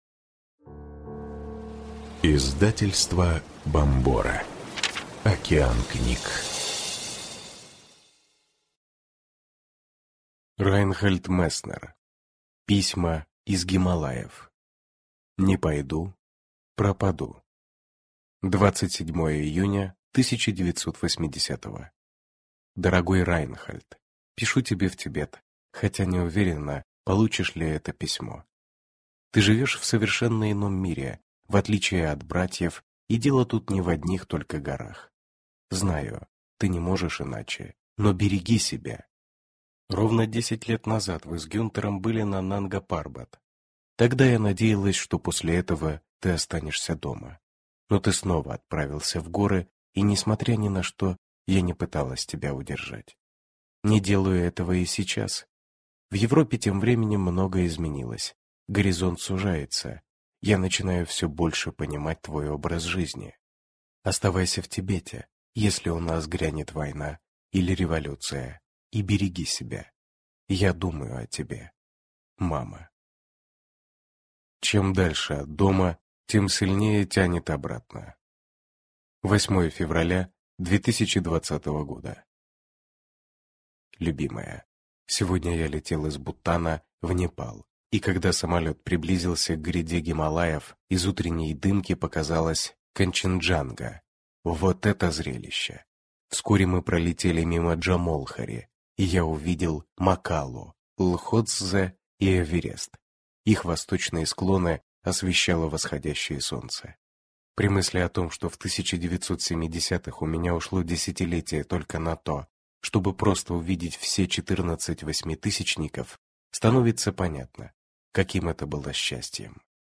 Аудиокнига Письма из Гималаев | Библиотека аудиокниг